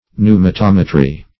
Pneumatometry \Pneu`ma*tom"e*try\, n.